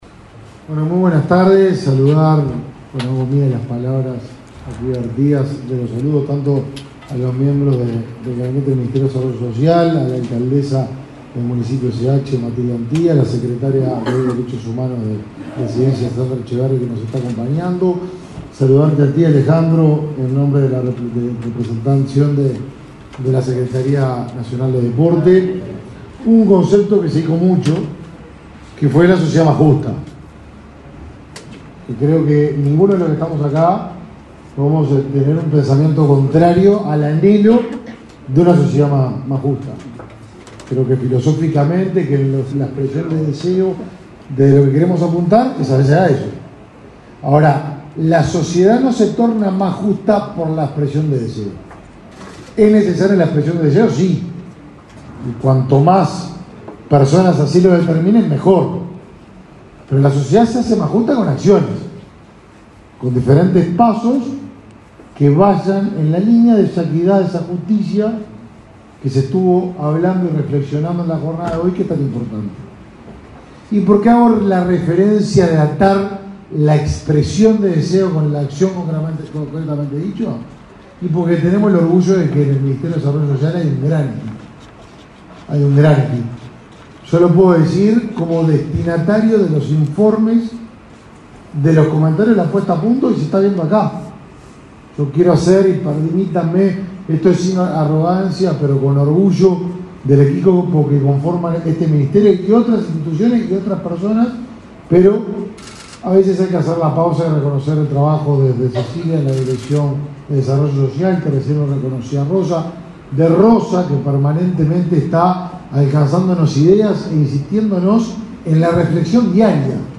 Conferencia de prensa por el lanzamiento del Mes de la Diversidad